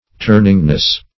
turningness - definition of turningness - synonyms, pronunciation, spelling from Free Dictionary
Search Result for " turningness" : The Collaborative International Dictionary of English v.0.48: Turningness \Turn"ing*ness\, n. The quality of turning; instability; tergiversation.